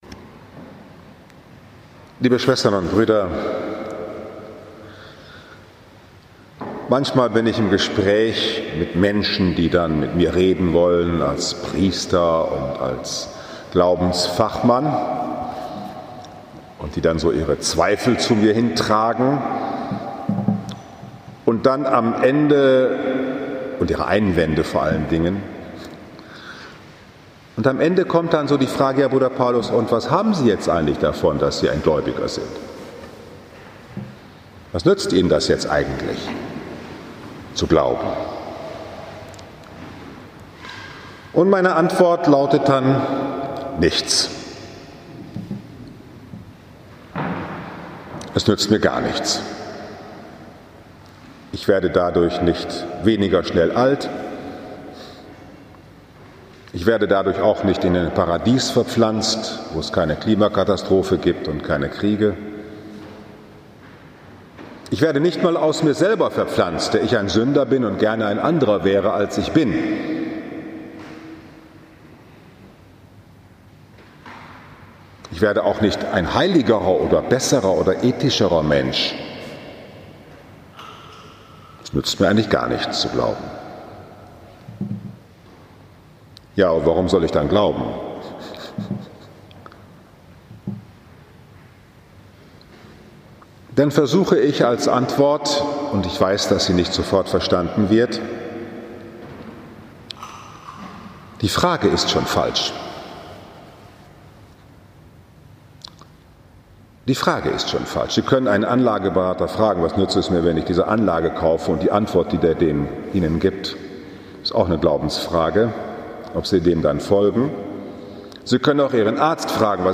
2. Oktober 2022, 11 Uhr, Liebfrauenkirche Frankfurt am Main, 27. Sonntag im Jahreskreis, Lesejahr C Von der Freiheit des Christen und der Lebensart Jesu, Menschen vom Zweckdenken zu befreien Spiritualität stört Die Selbstoptimierer sind los!